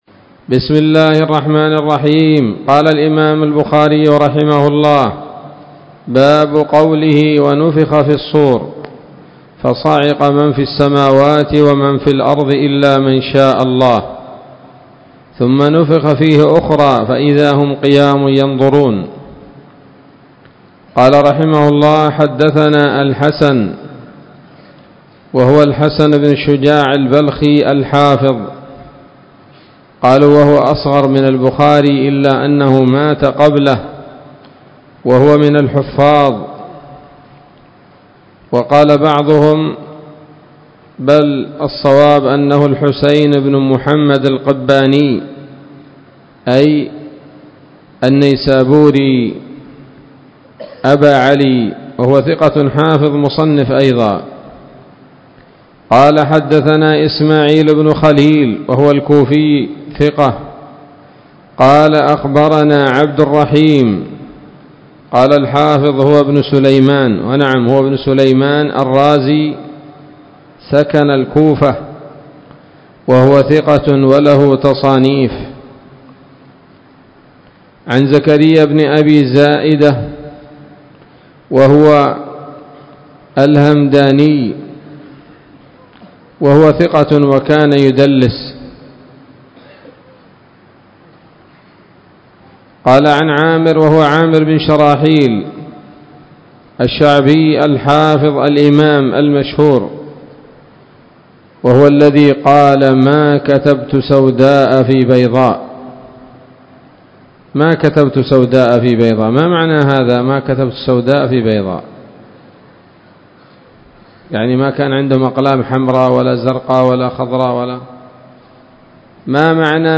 الدرس التاسع عشر بعد المائتين من كتاب التفسير من صحيح الإمام البخاري